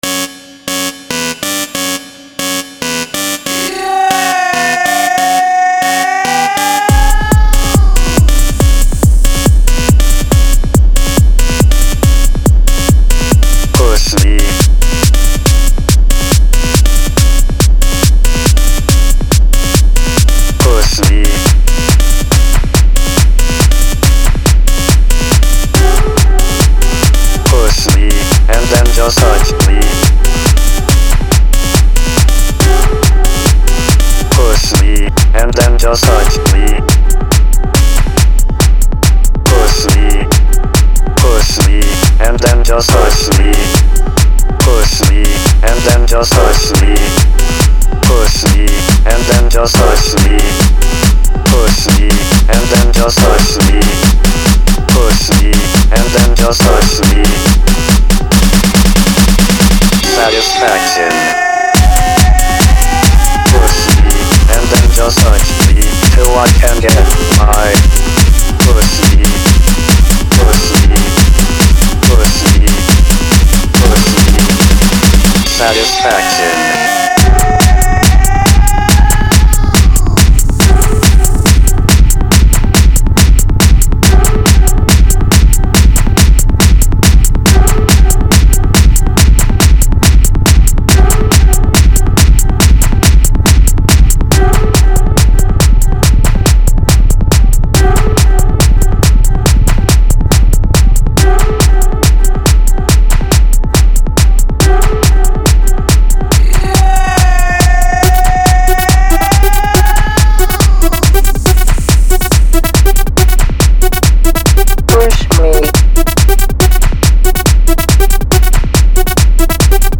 מין סוג של רמיקס